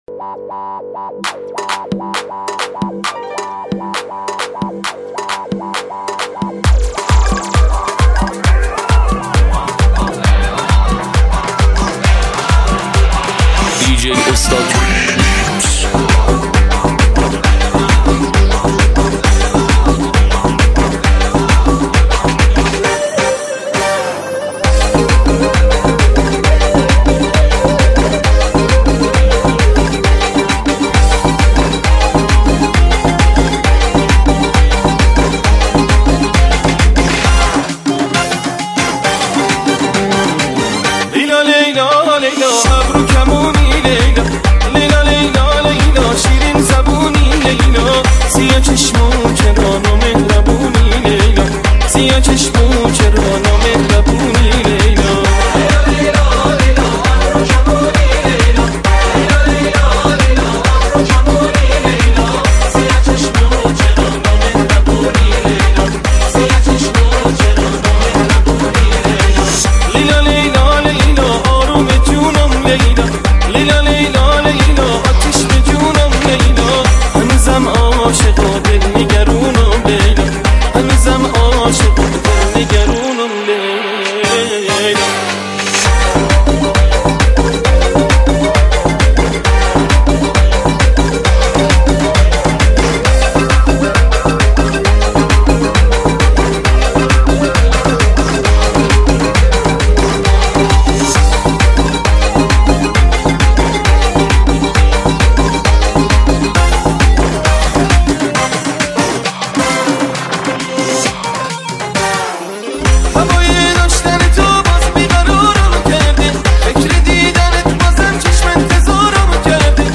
پخش آنلاین ریمیکس